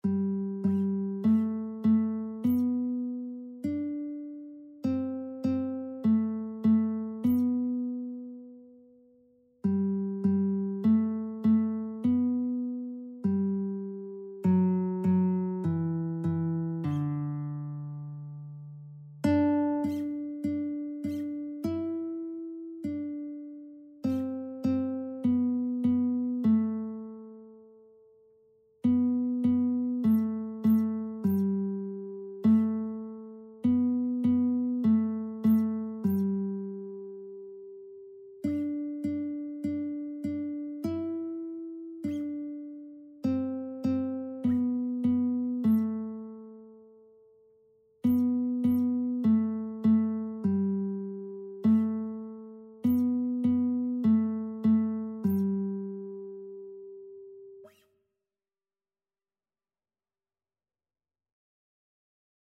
Christian
4/4 (View more 4/4 Music)
Classical (View more Classical Lead Sheets Music)